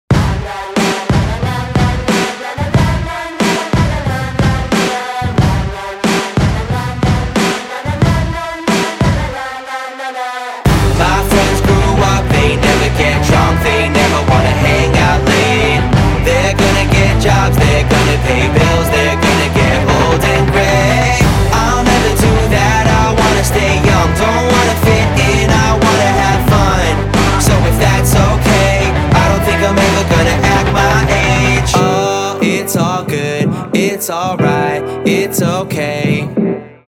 • Качество: 320, Stereo
позитивные
саундтреки